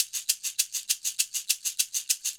Shaker 07.wav